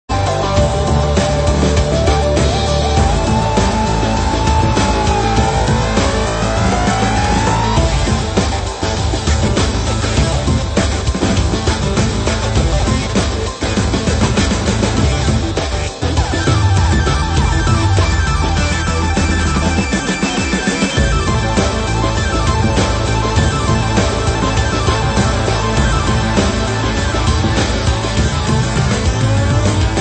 Your one-stop site for Commodore 64 SID chiptune remixes.